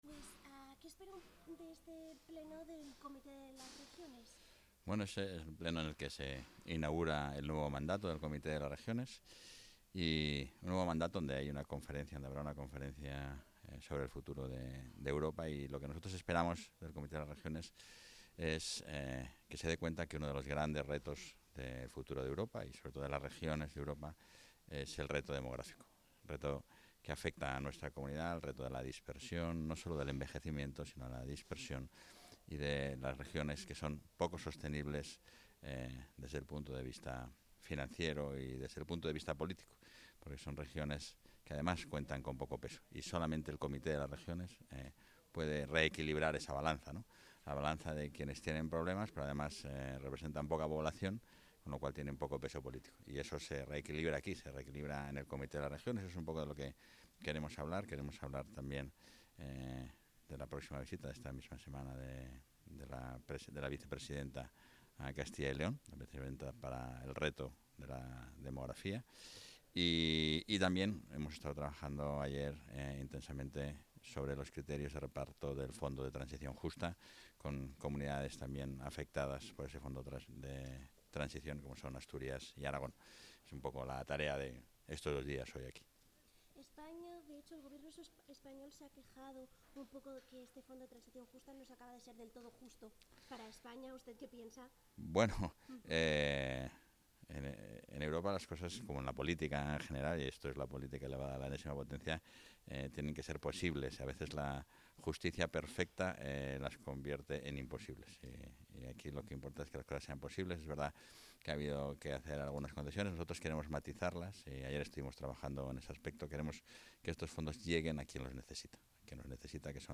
Audio del vicepresidente.